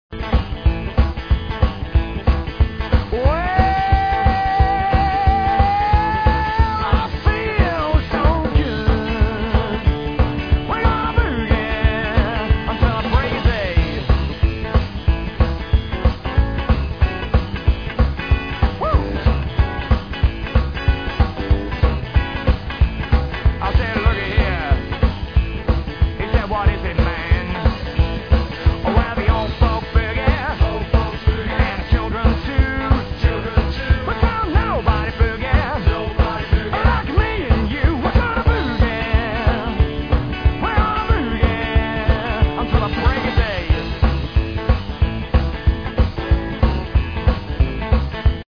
All samples are 8bit 11KHz mono recordings
Rhythm & Blues experience you'll never forget